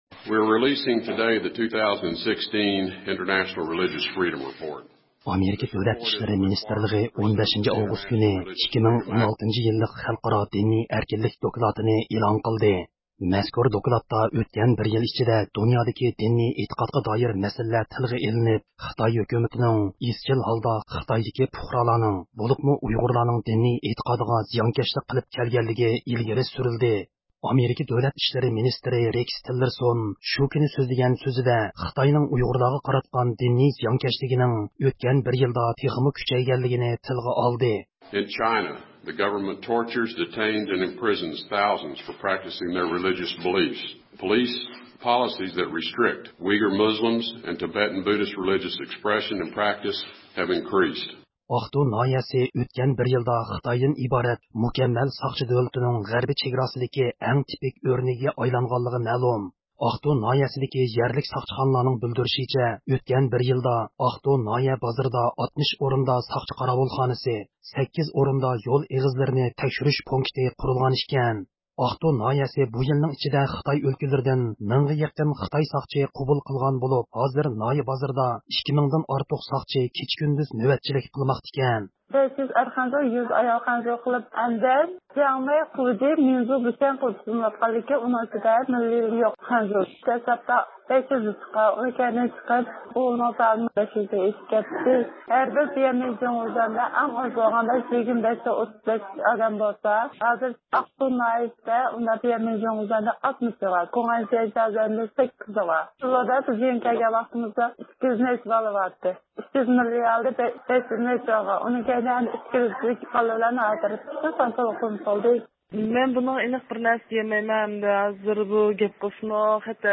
ھەپتىلىك خەۋەرلەر (12-ئاۋغۇستتىن 18-ئاۋغۇستقىچە) – ئۇيغۇر مىللى ھەركىتى